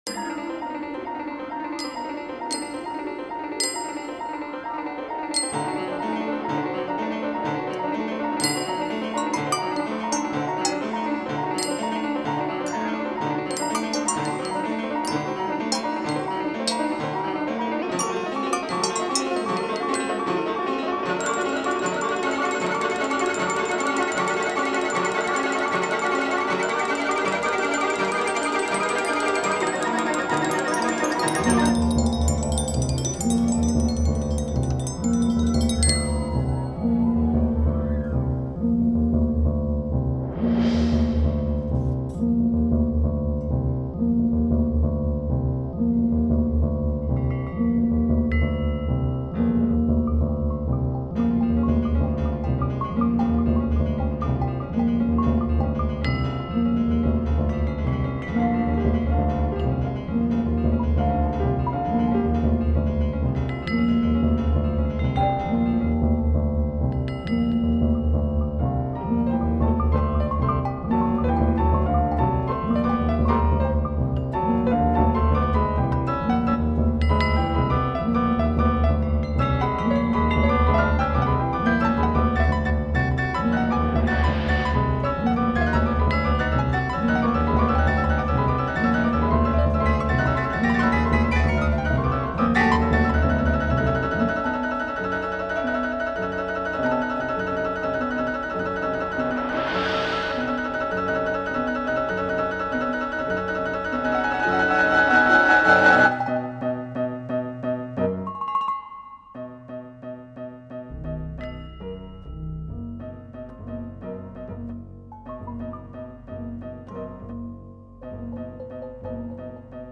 keyboards
percussions
double bass
guitar